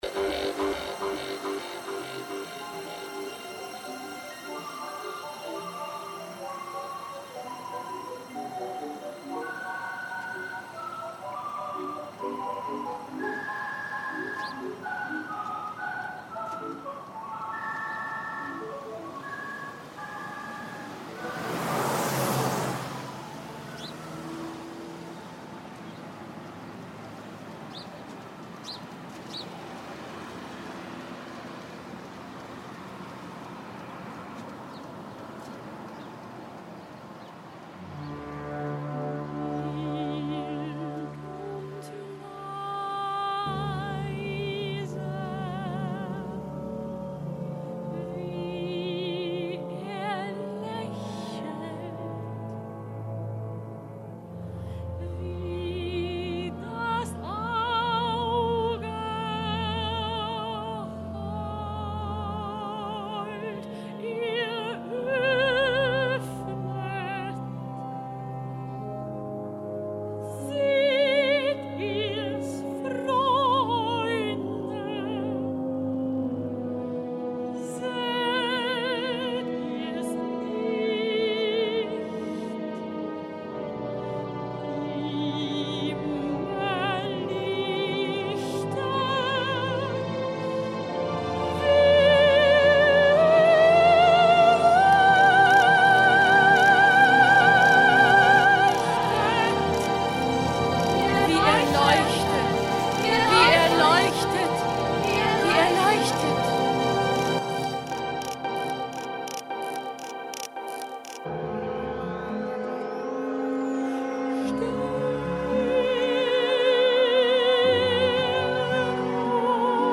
Opernremix: